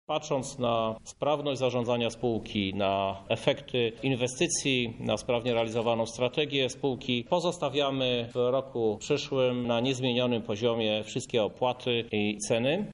O szczegółach mówi Krzysztof Żuk, prezydent Lublina.